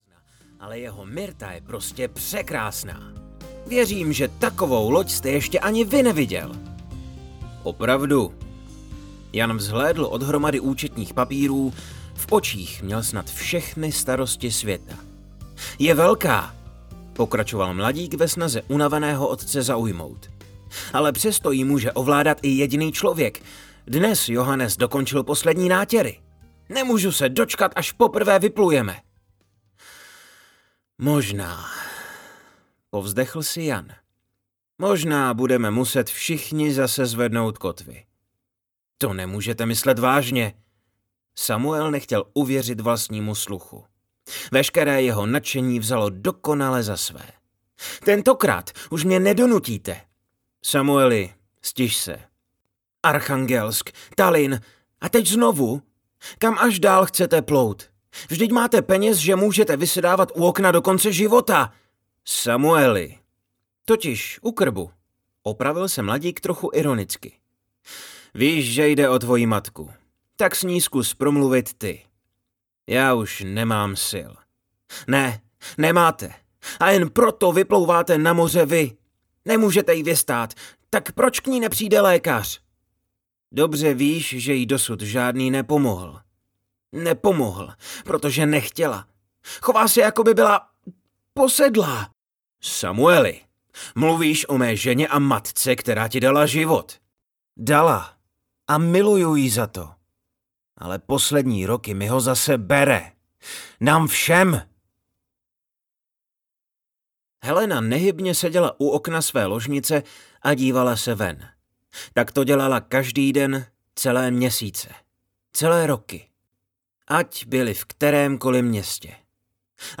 Ti nepohřbení audiokniha
Ukázka z knihy